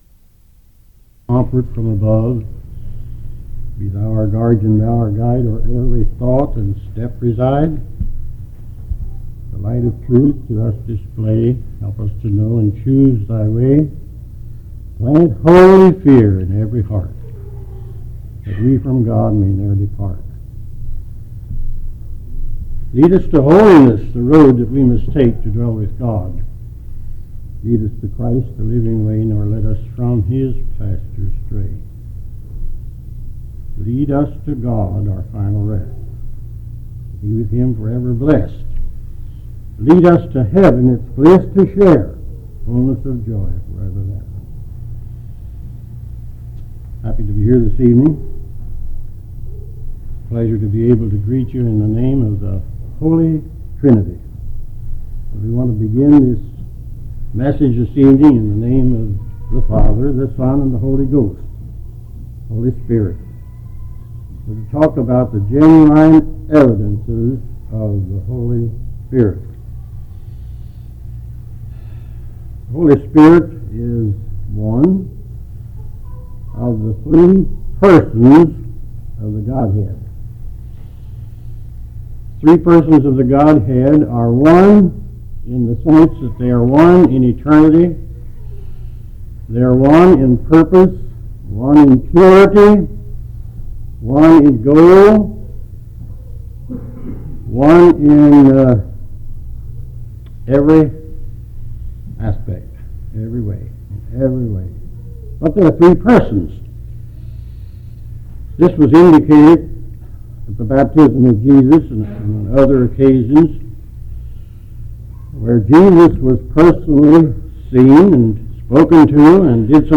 How do we know we have the Holy Spirit in our lives? Why doesn't the Holy Spirit give the same signs today as in Acts 2? Preached in Pensacola Florida in March of 1997, this sermon still addresses questions sincere believers may wrestle with today.